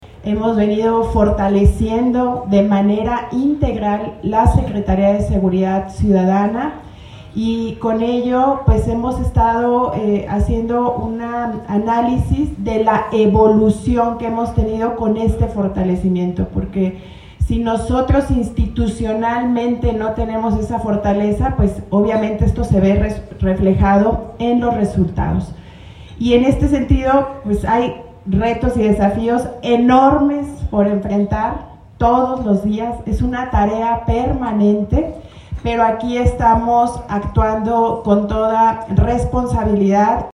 Lorena Alfaro García, presidenta municipal